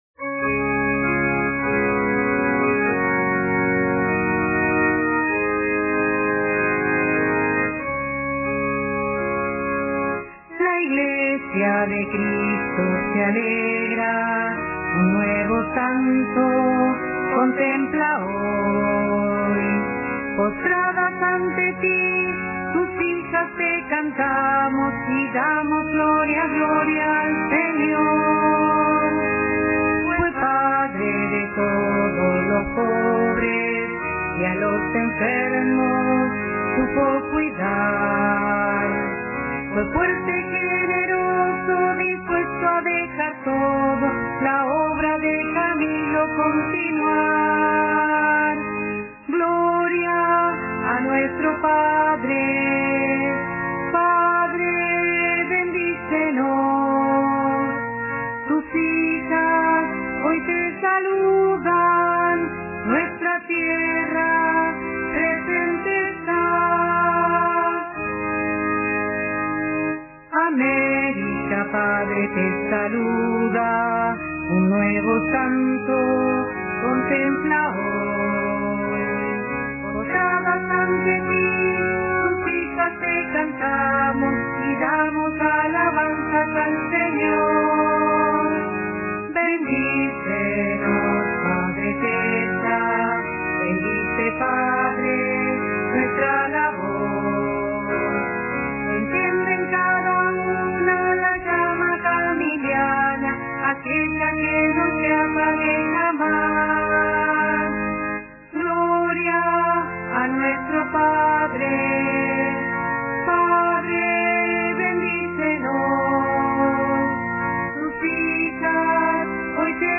Himno al Padre Luis Tezza (Himno)